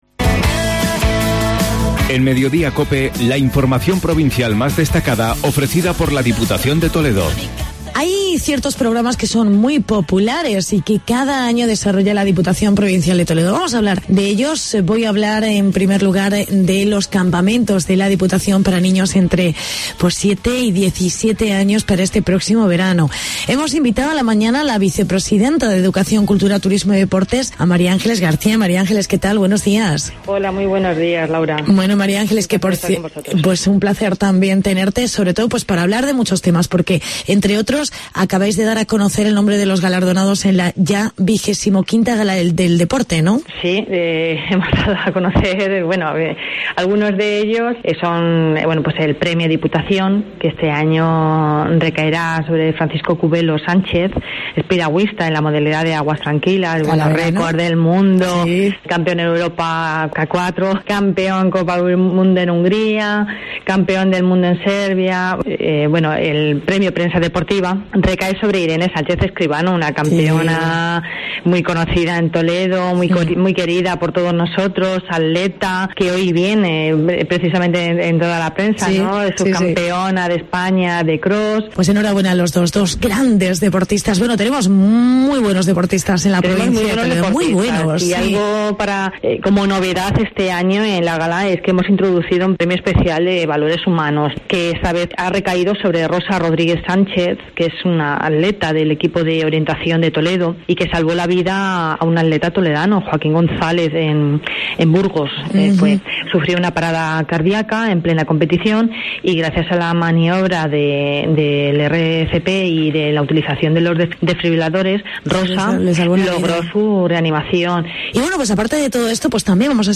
Entrevista con la diputada Mª Ángeles García